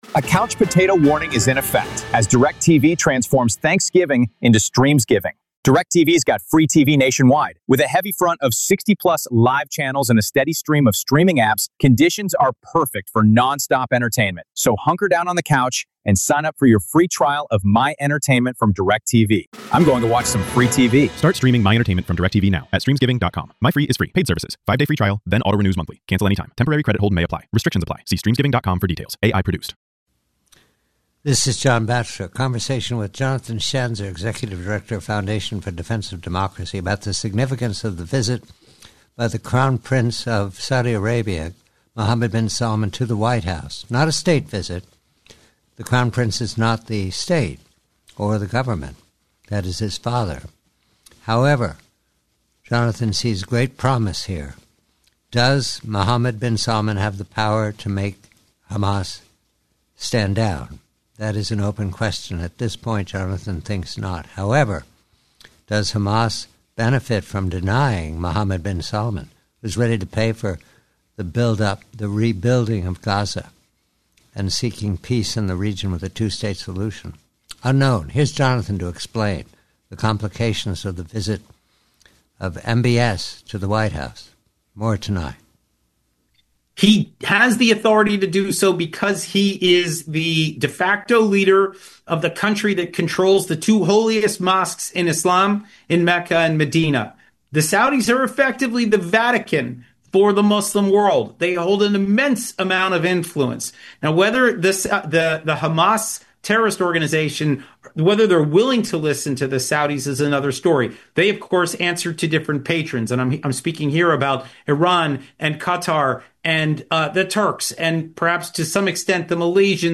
Headliner Embed Embed code See more options Share Facebook X Subscribe PREVIEW: Rail Sabotage in Poland, Quid Bono? Guest: